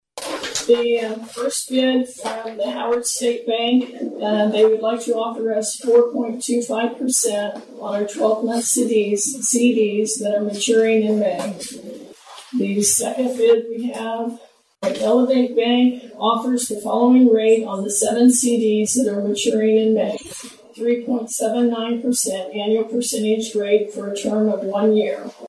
At this week's meeting council members had offers from two area banks.
shares the rates during the open meeting.